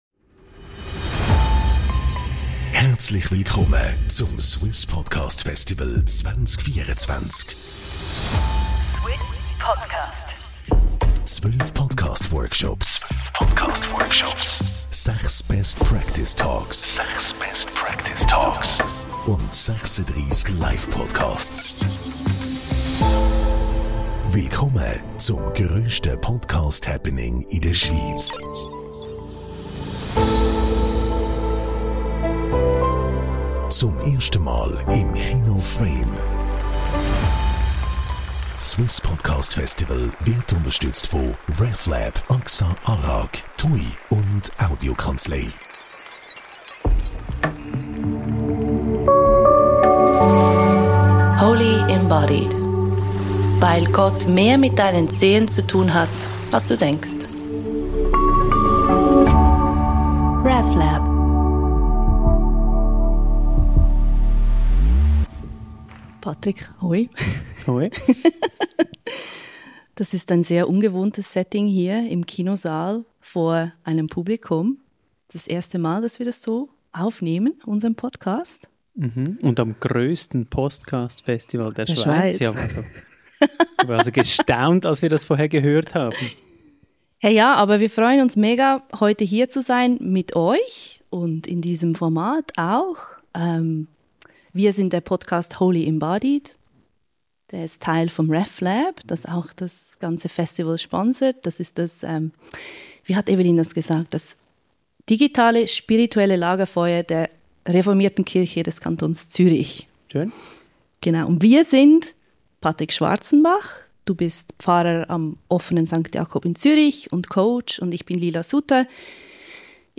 Dabei ist die Folge entstanden, die ihr diese Woche hört – inklusive Stimmen aus dem Publikum zum grossen, super aktuellen Thema: Was benötigen wir, um die Zukunft zu meistern?